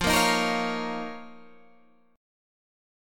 F7sus4#5 Chord